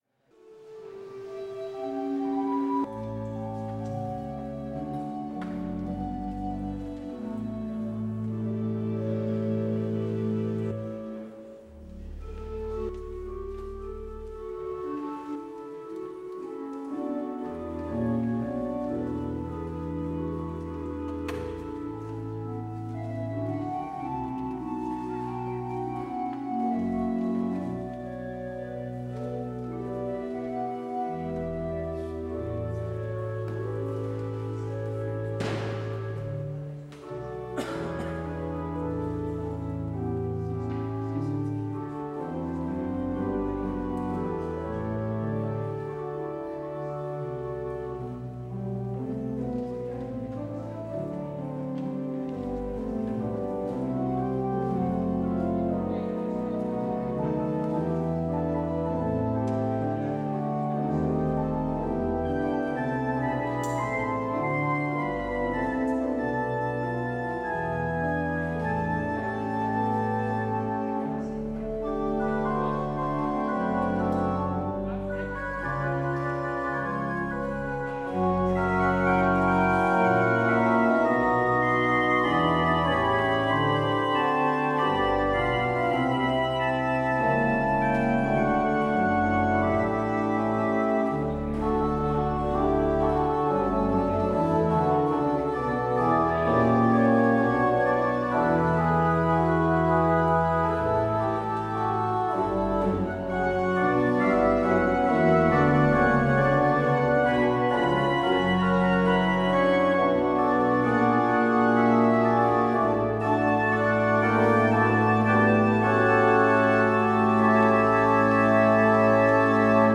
Het slotlied is: NLB 362: 1, 2 en 3.